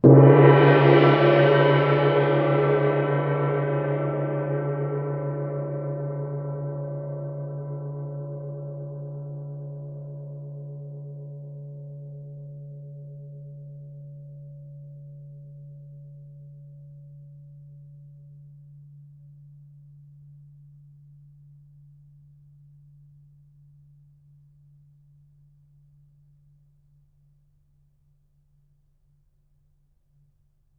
gongHit_f.wav